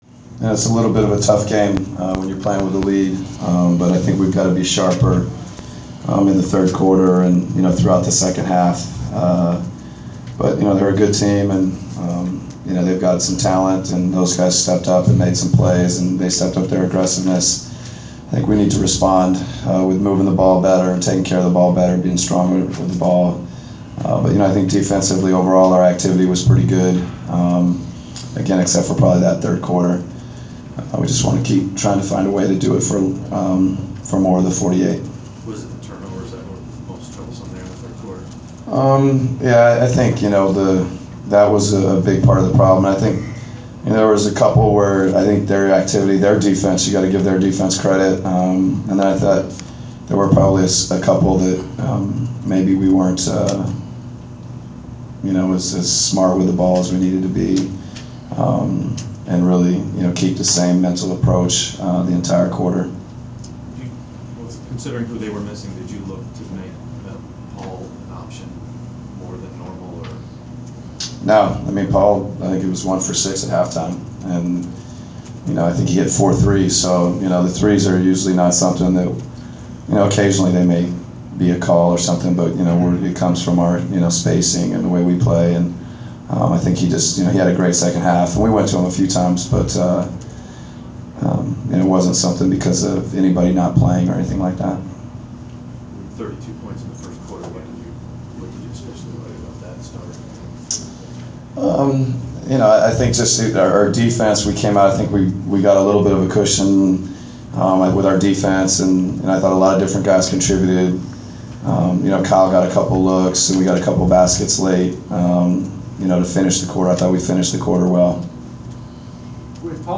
Inside the Inquirer: Postgame interview with Atlanta Hawks’ coach Mike Budenholzer (12/7/14)
We attended the postgame press conference of Atlanta Hawks’ head coach Mike Budenholzer following the team’s 96-84 home win over the Denver Nuggets on Dec. 7. Topics included the Hawks’ defensive effort, playing with a lead and contributions of Paul Millsap.